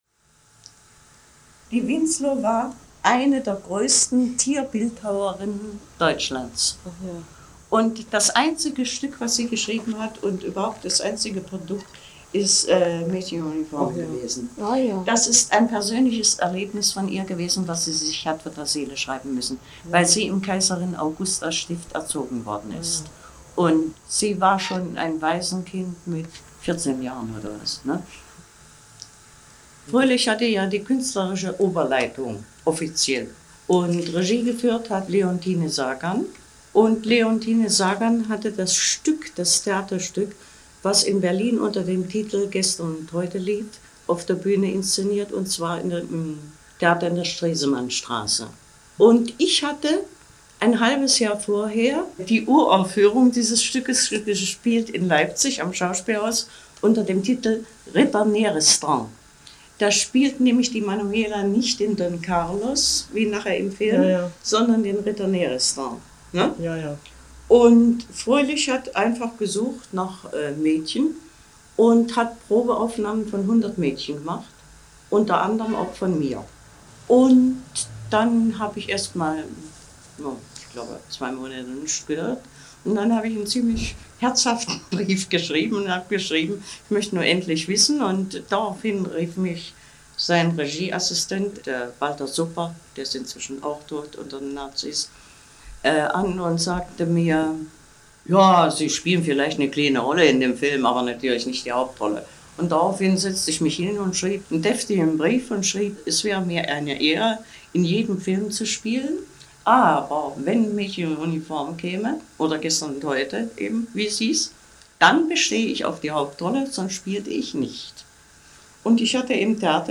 Interview mit Hertha Thiele